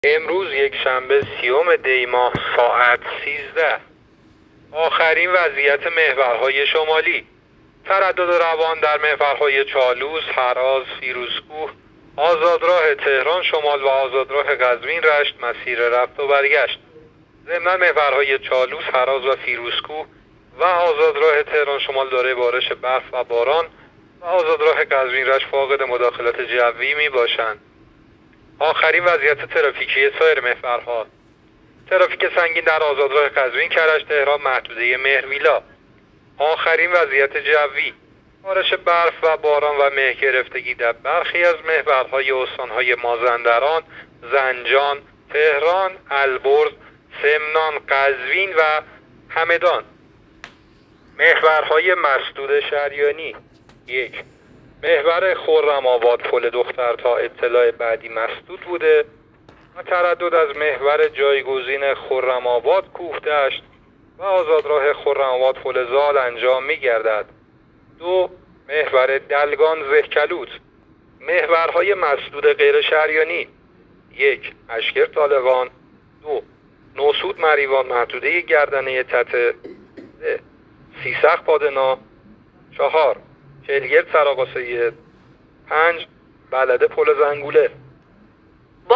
گزارش رادیو اینترنتی از آخرین وضعیت ترافیکی جاده‌ها تا ساعت ۱۳ سی‌ام دی؛